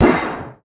clang1.ogg